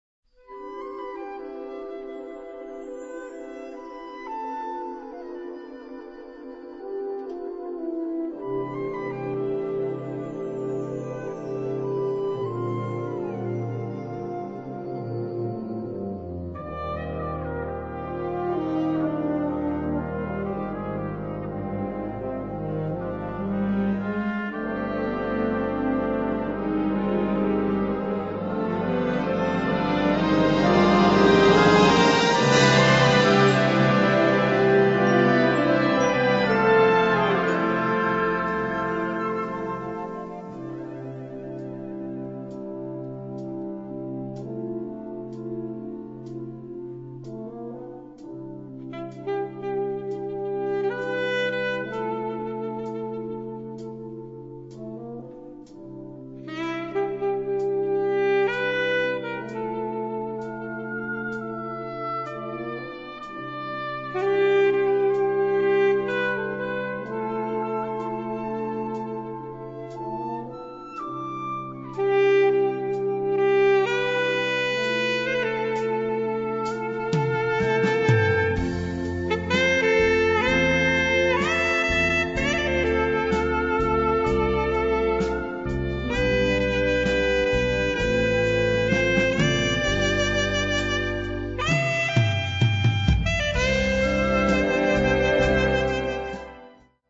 Gattung: Moderne Blasmusik
Besetzung: Blasorchester
Mit Solo für Alt-Saxophon.